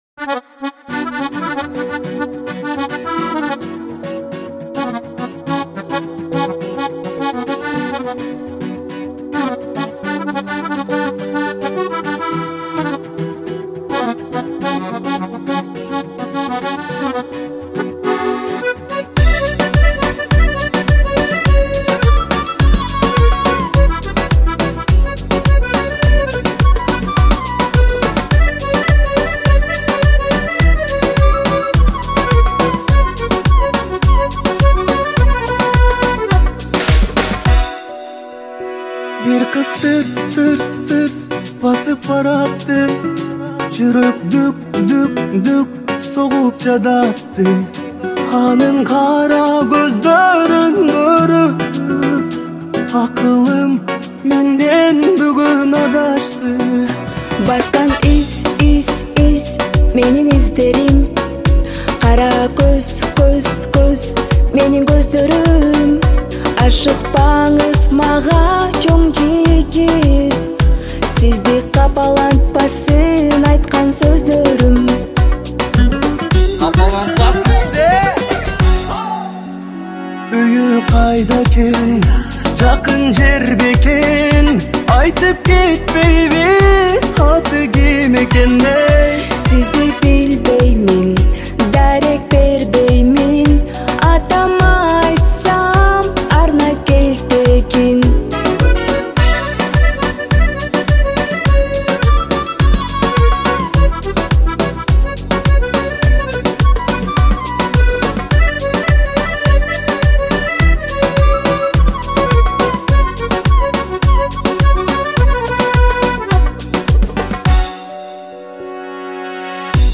• Категория: Киргизские песни